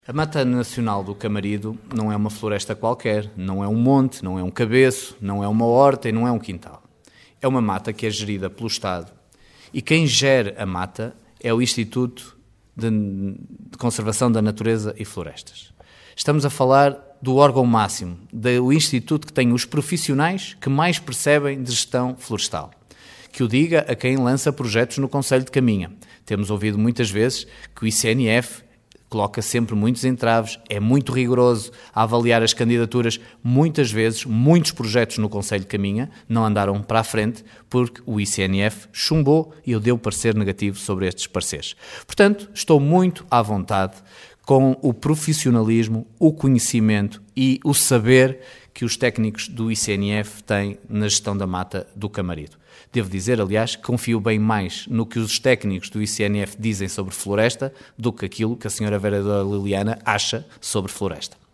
Opinião bem distinta manifestou o presidente da Câmara de Caminha, Miguel Alves que diz confiar no ICNF e nos seus profissionais, um organismo que considera ser bastante rigoroso.